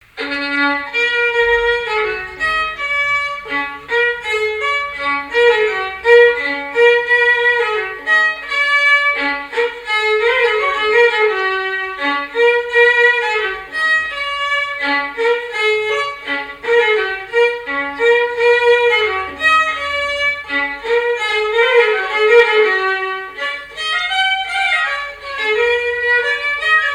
Mémoires et Patrimoines vivants - RaddO est une base de données d'archives iconographiques et sonores.
danse : scottich trois pas
répertoire musical au violon
Pièce musicale inédite